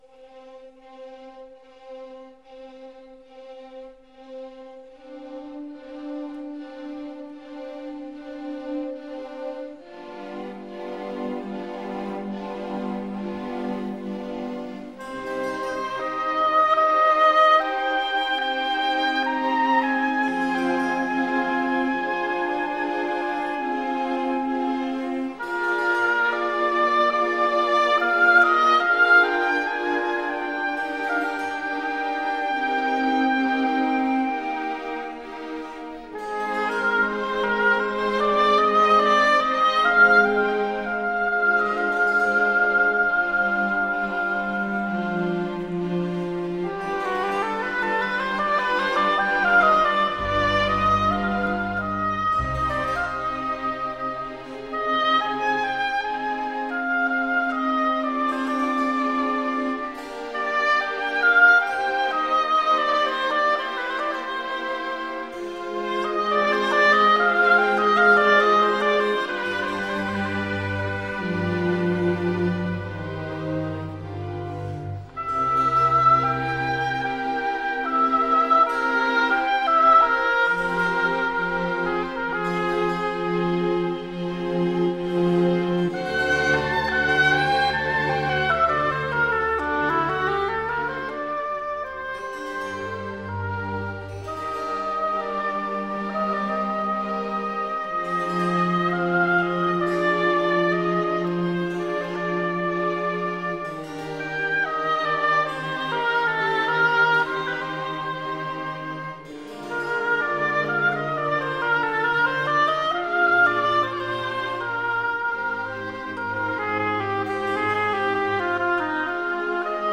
Género: Classical.